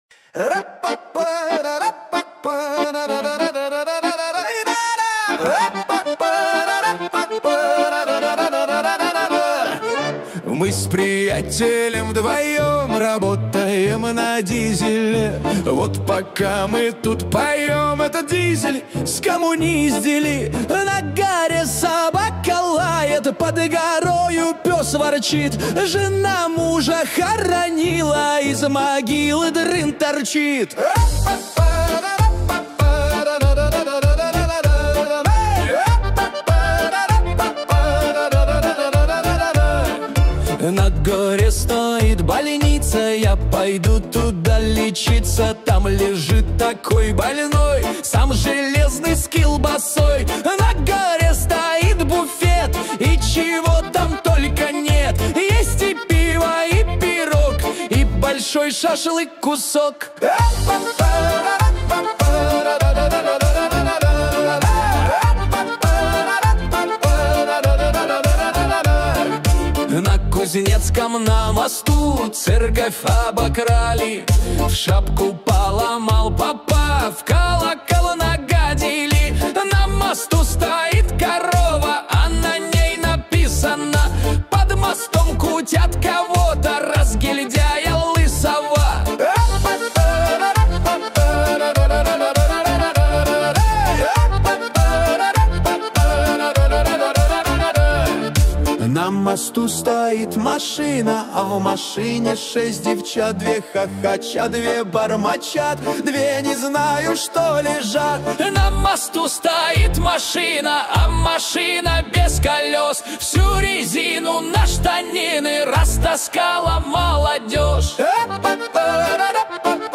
13 декабрь 2025 Русская AI музыка 76 прослушиваний